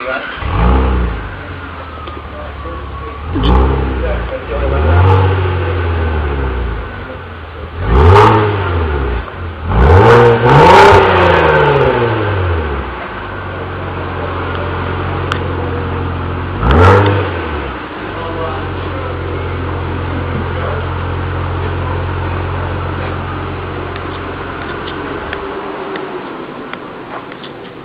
Hm; grem ven, stopim okoli avta in ugotovim, da motor teče, in to tako tiho, da se ga dejansko ne sliši. V6 obratuje zares uglajeno, vsake toliko časa pa ga izda le hladilni sistem.